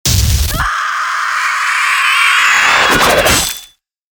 FX-218-HALLOWEEN-STAGER
FX-218-HALLOWEEN-STAGER.mp3